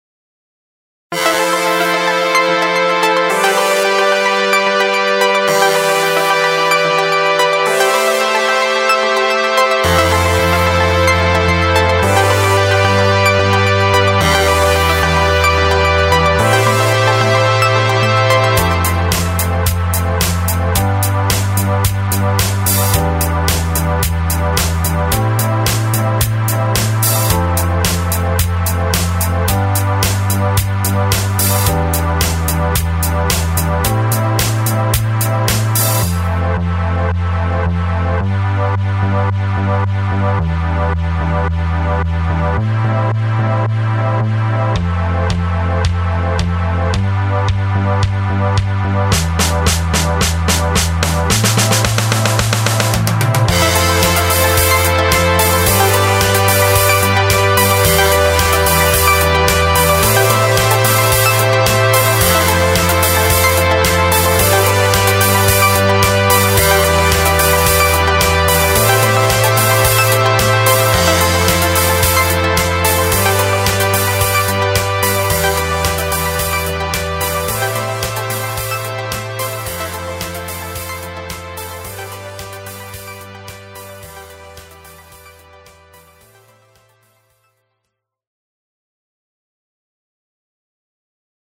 BGM
EDMショート